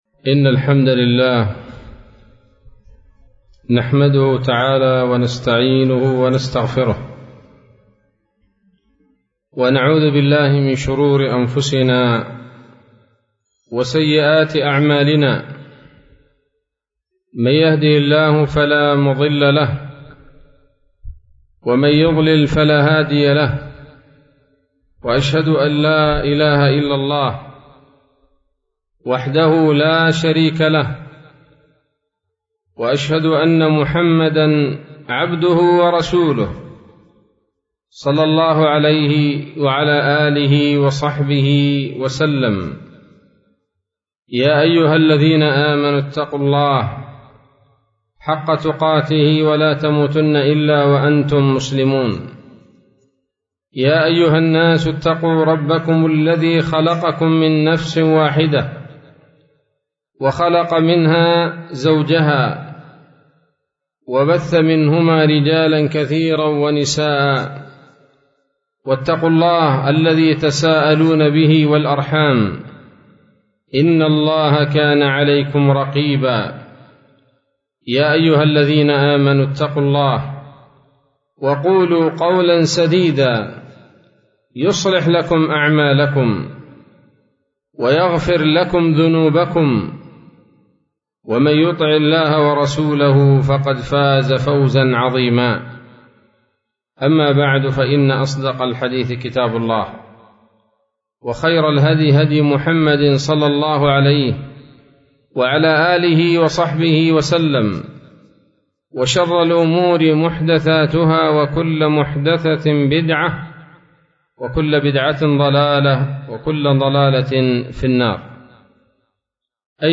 ليلة الثلاثاء 20 ذو الحجة 1443هـ، بمسجد التوحيد - قرية الحفاة - حطيب - يافع